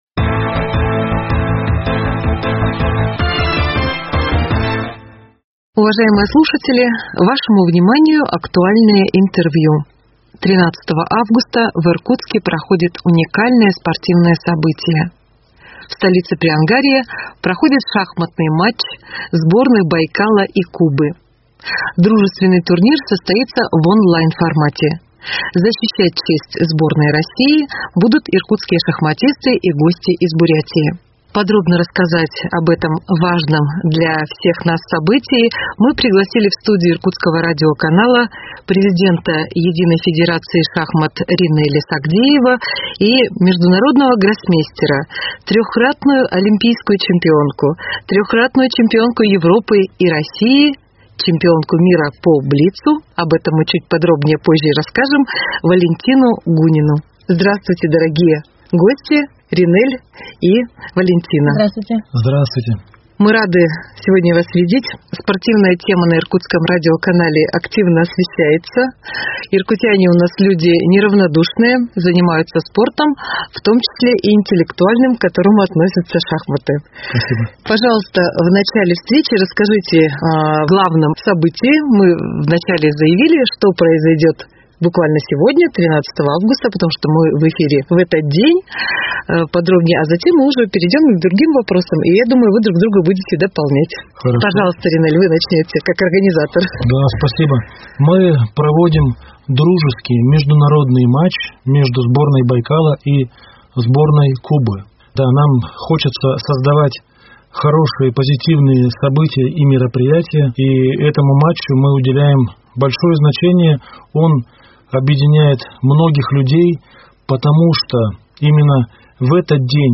Актуальное интервью: Шахматный матч Байкал-Куба в Иркутске 13.08.2021